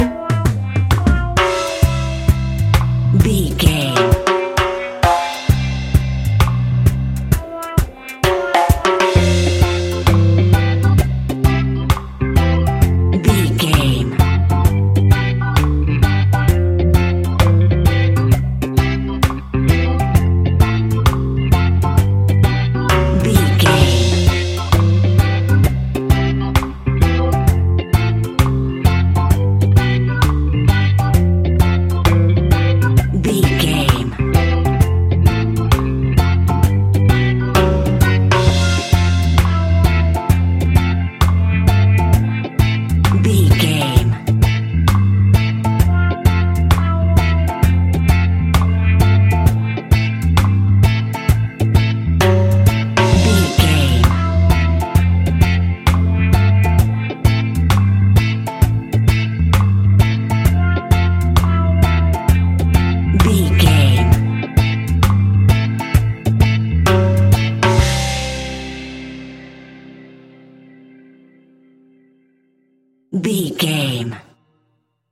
Classic reggae music with that skank bounce reggae feeling.
Ionian/Major
dub
instrumentals
laid back
chilled
off beat
drums
skank guitar
hammond organ
percussion
horns